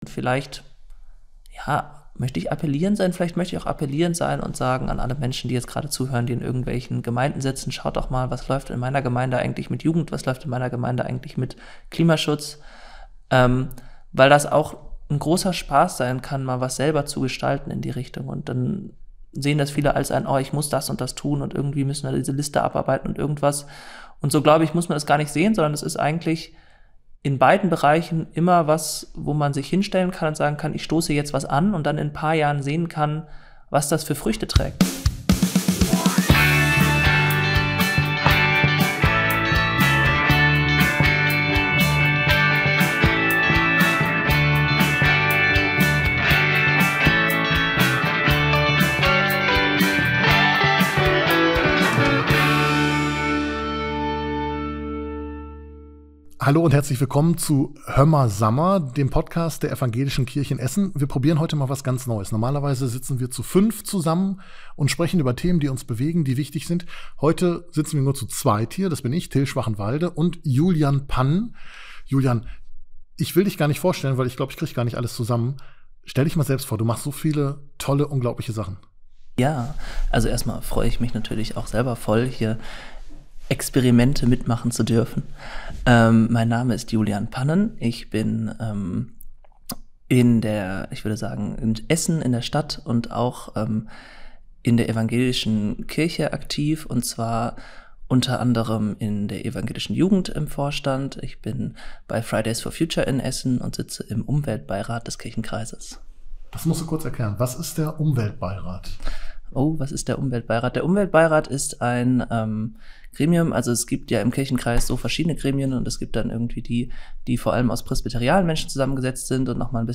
Interview Spezial ~ Hömma, Samma Podcast